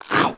Index of /App/sound/monster/skeleton_soldier_spear
attack_2.wav